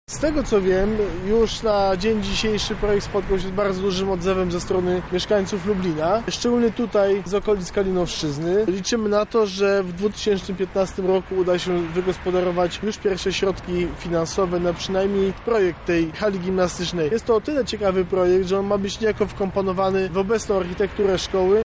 O szczegółach mówi Piotr Kowalczyk, przewodniczący Rady Miasta